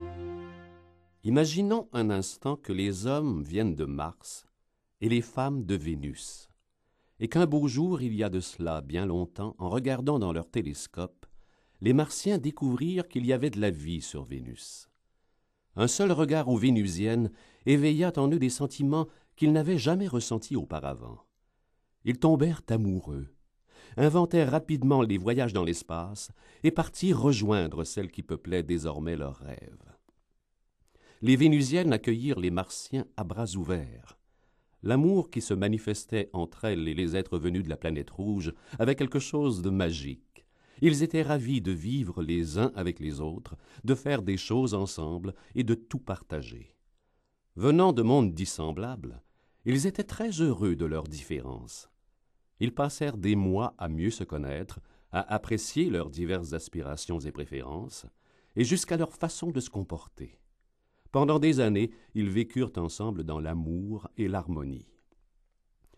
Extrait gratuit - Les hommes viennent de Mars, les femmes viennent de Vénus de John Gray